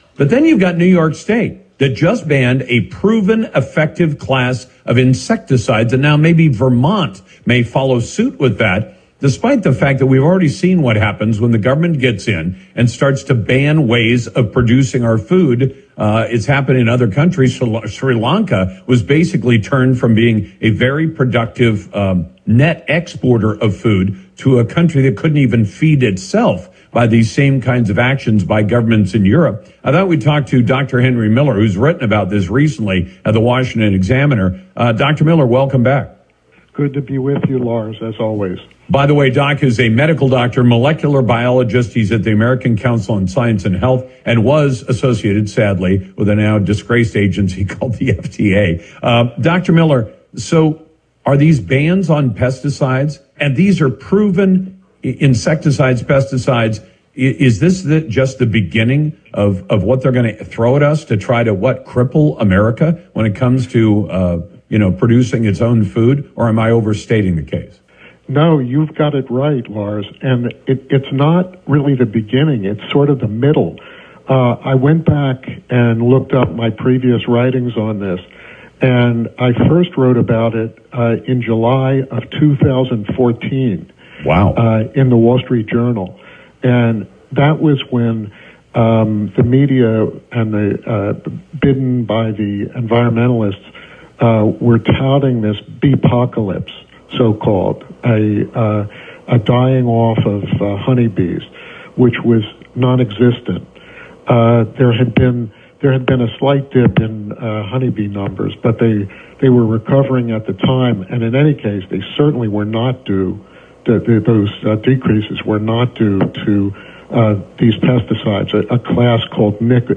You can find the entire conversation